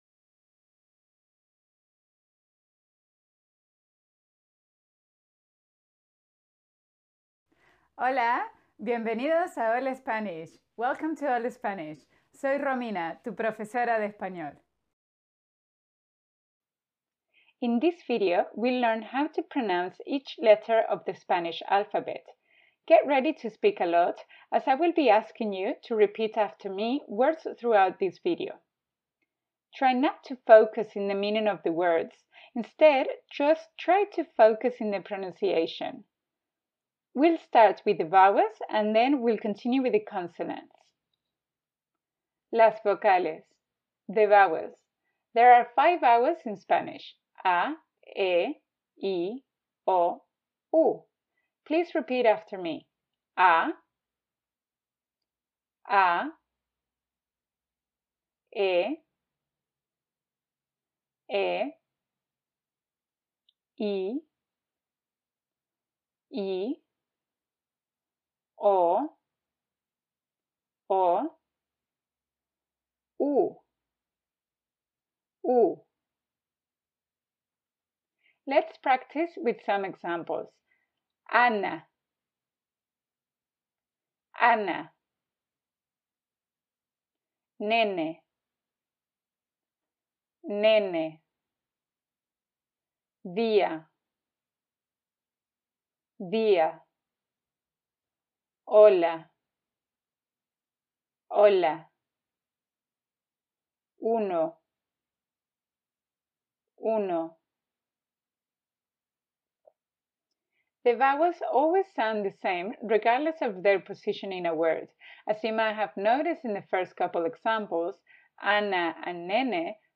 Guía de pronunciación - Pronunciation guide